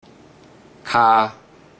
Click each Romanised Teochew word or phrase to listen to how the Teochew word or phrase is pronounced.
kha3